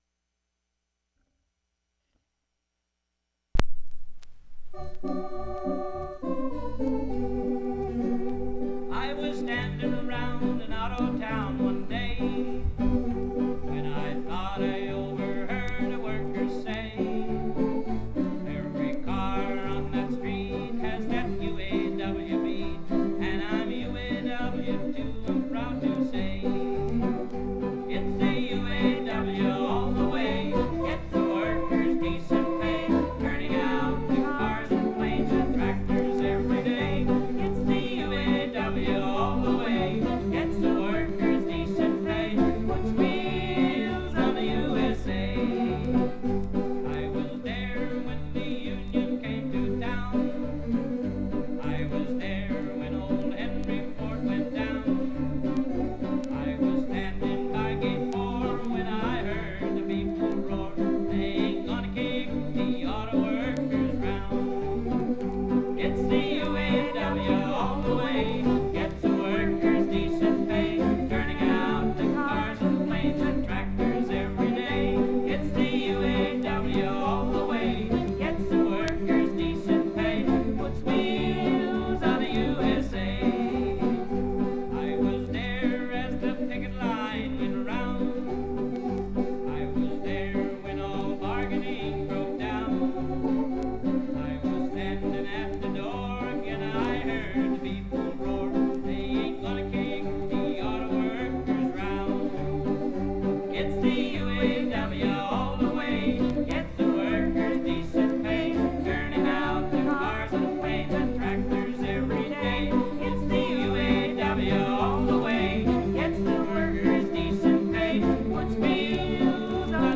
It's the UAW All the Way · Songs of the UAW · Omeka S Multi-Repository
guitar